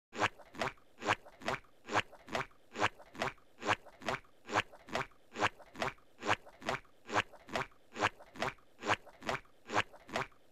Squidward walking sound effect.mp3